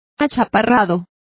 Complete with pronunciation of the translation of stockiest.